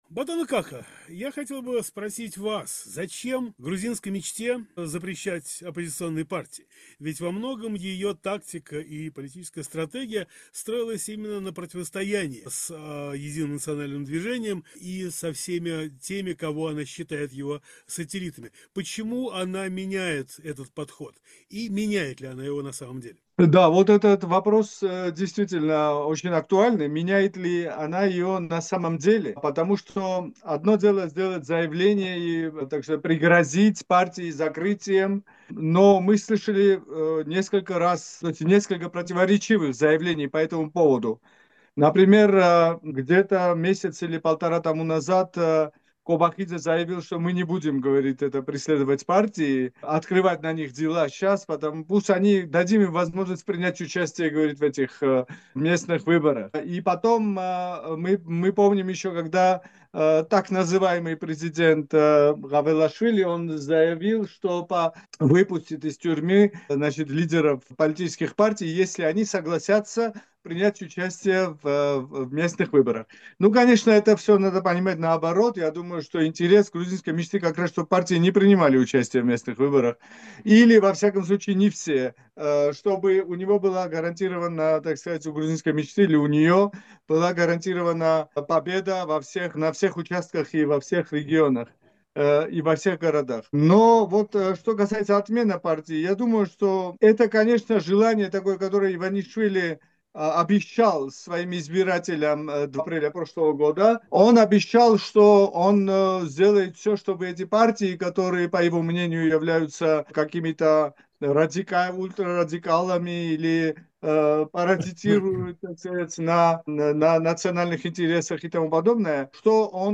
Беседа с экспертами за "Некруглым столом"